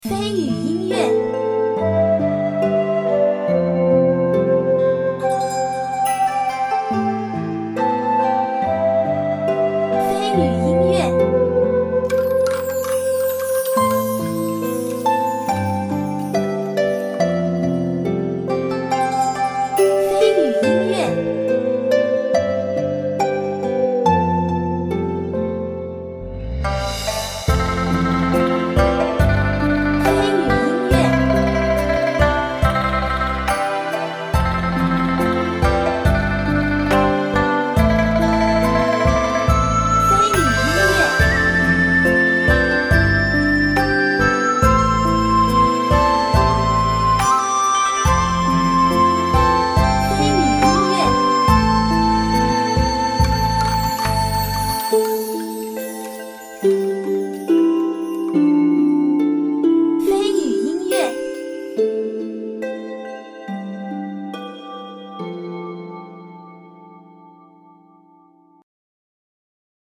音乐试听 古风 恢宏 抒情 https